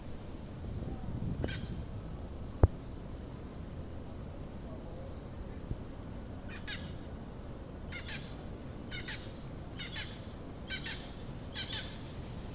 Cotorra de La Española (Amazona ventralis)
Nombre en inglés: Hispaniolan Amazon
Localidad o área protegida: Complejo Bahia Principe - Punta Cana
Condición: Silvestre
Certeza: Fotografiada, Vocalización Grabada